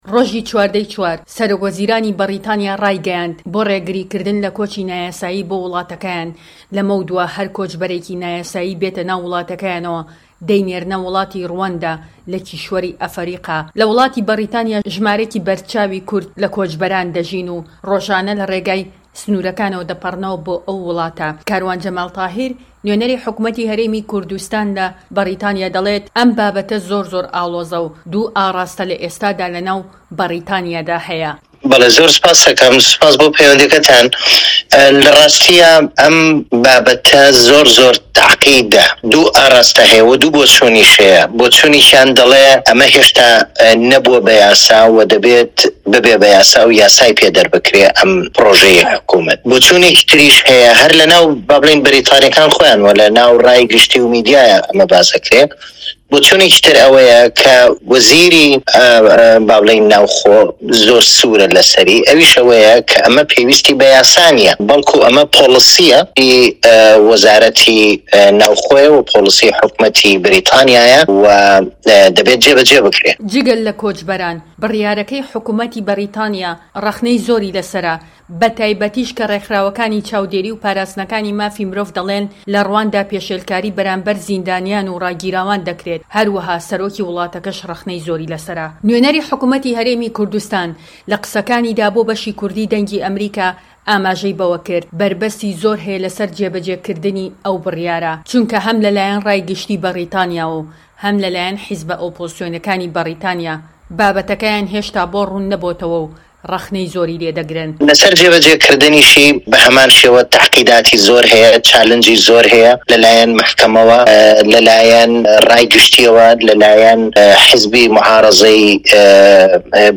هه‌رێمه‌ کوردیـیه‌کان - گفتوگۆکان
کاروان جەمال تاهیر نوێنەری حکومەتی هەرێم لە بەریتانیا